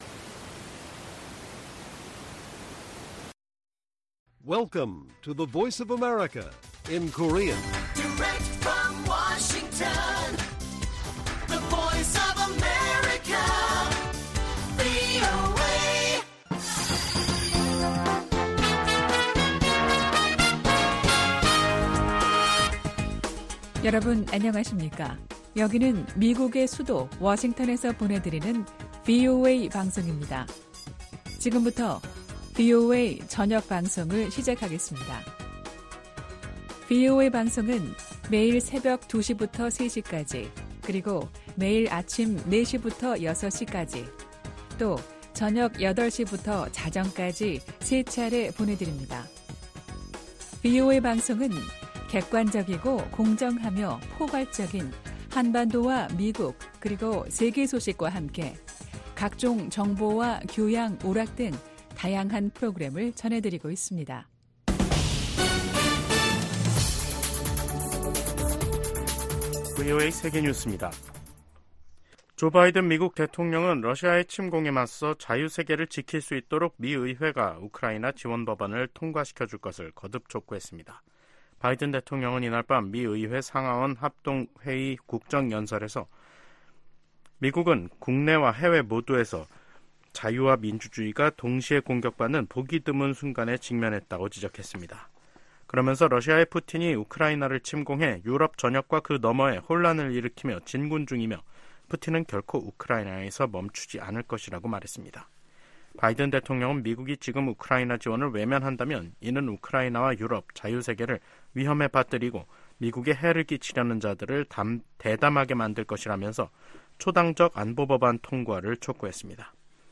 VOA 한국어 간판 뉴스 프로그램 '뉴스 투데이', 2024년 3월 8일 1부 방송입니다. 조 바이든 미국 대통령이 국정연설에서 자유 세계를 지키기 위해 우크라이나를 침공한 러시아를 막아야 한다고 강조했습니다. '프리덤실드' 미한 연합훈련이 진행 중인 가운데 김정은 북한 국무위원장이 서울 겨냥 포사격 훈련을 지도했습니다.